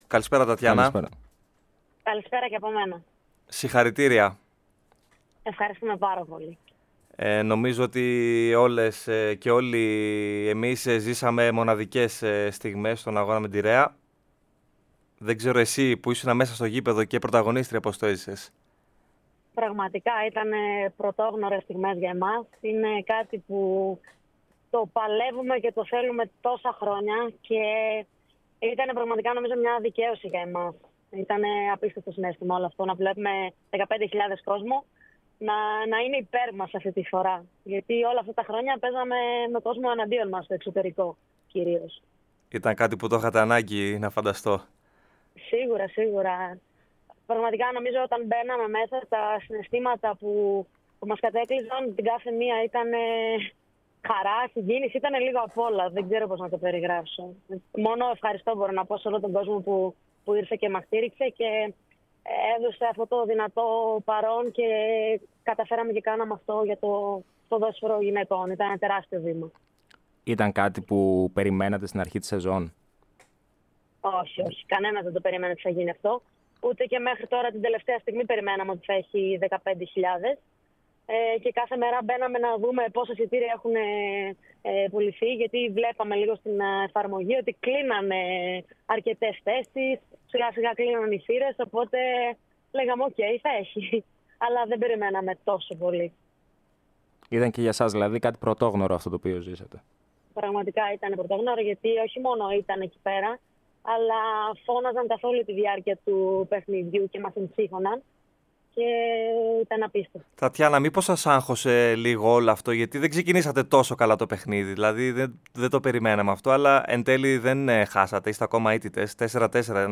Αναλυτικά η συνέντευξη